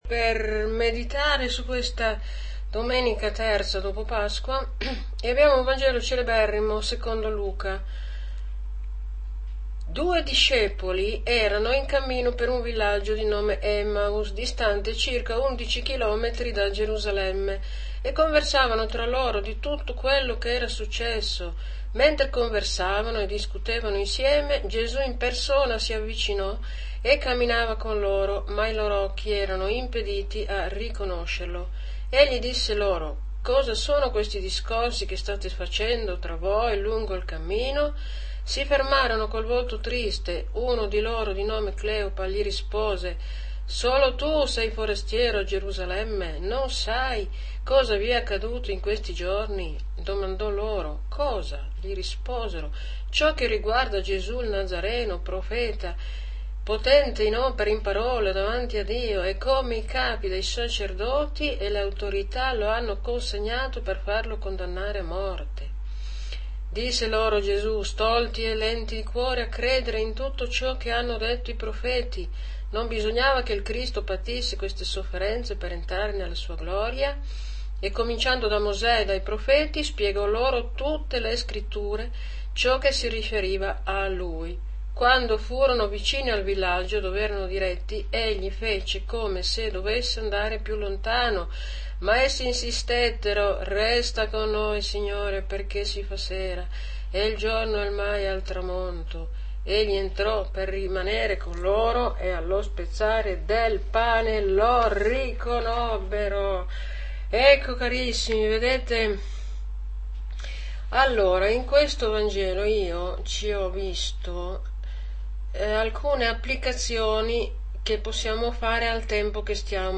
AUDIO Audio commento alla liturgia - Lc 24,13-35